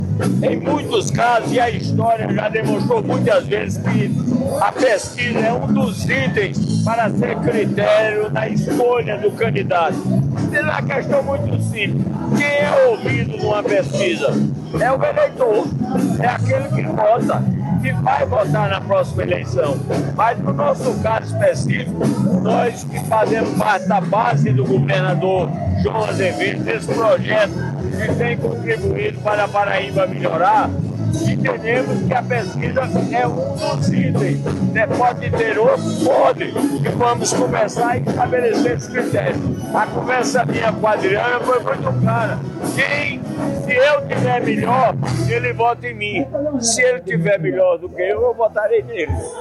Durante visita à cidade de Jacaraú, no último final de semana, o prefeito de João Pessoa, Cícero Lucena (PP), confirmou à imprensa paraibana que firmou um acordo político com o presidente da Assembleia Legislativa da Paraíba, Adriano Galdino (Republicanos).